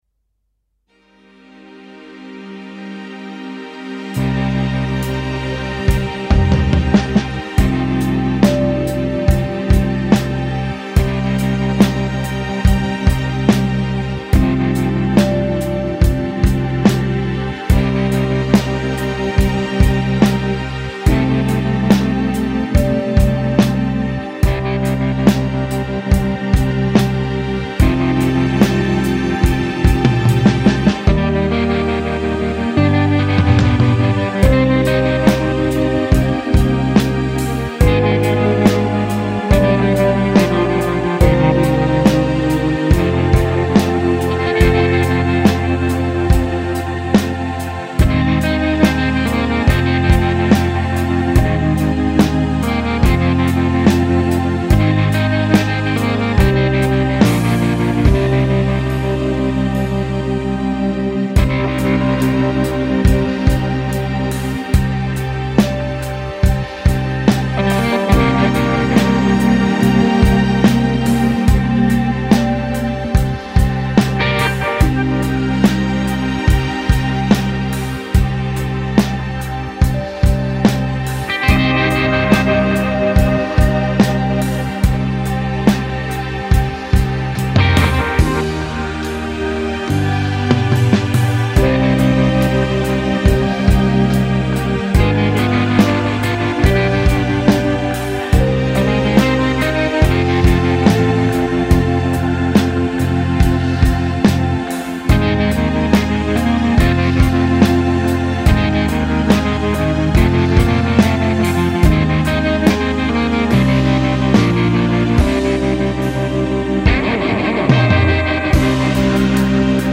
aerien - nostalgique - calme - guitare electrique - air